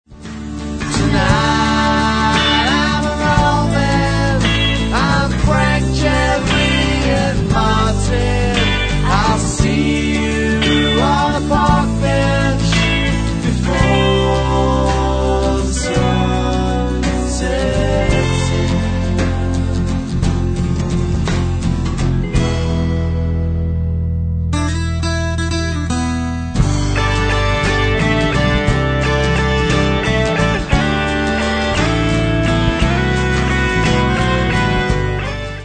Haunting mellow songs
dual vocals
bittersweet tunes